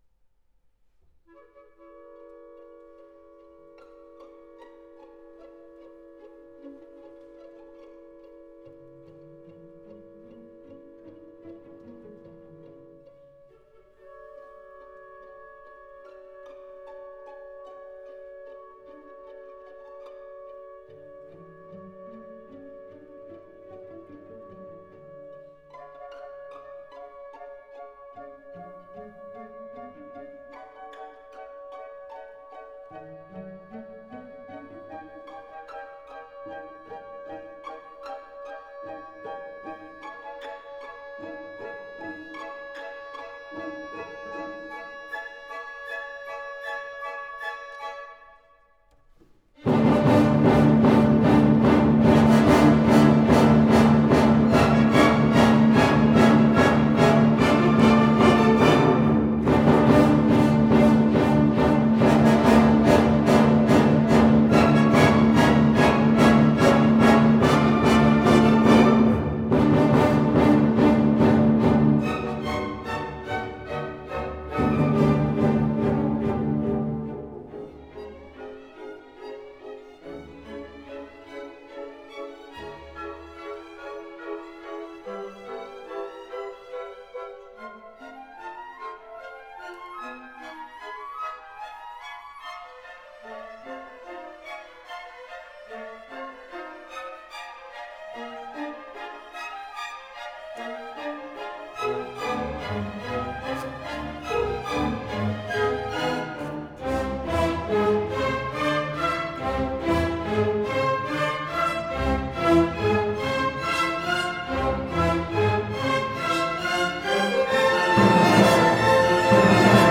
This performance by the Bruckner Akademie Orchester took place on April 8, 2002 in the Herkulessaal in Munich.